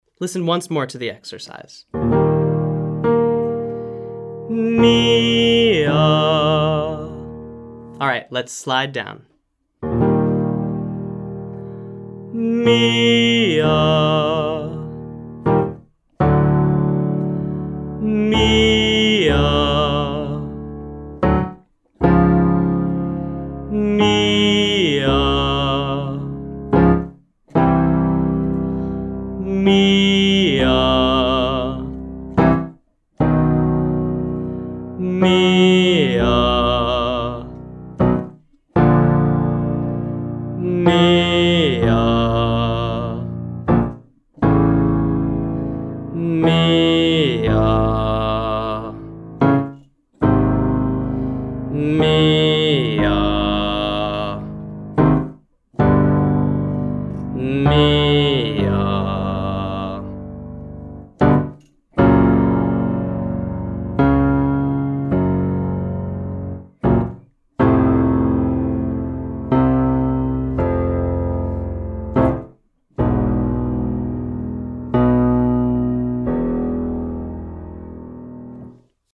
So in general, use a relaxed, medium-volume chest voice sound and allow your larynx to float and stay flexible and open.
I’ll sing as low as I can as a Tenor and then continue to play the piano a little lower for any Basses out there.